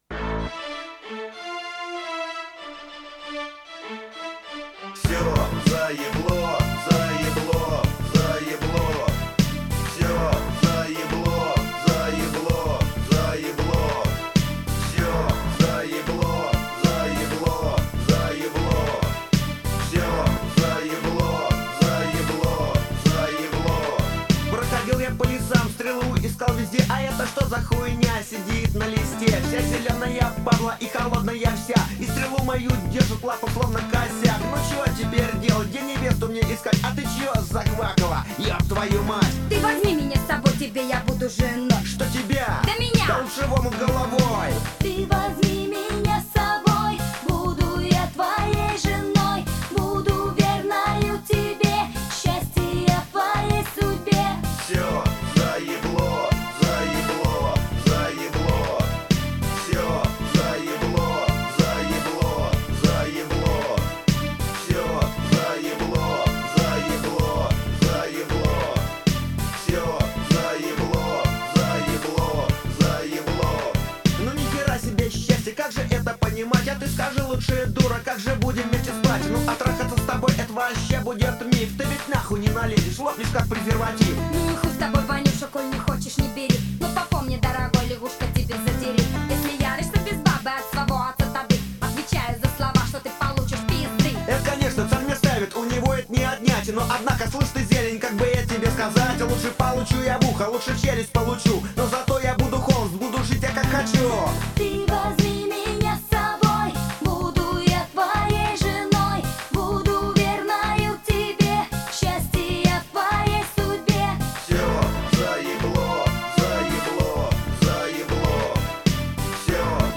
Жанр: Punk Rock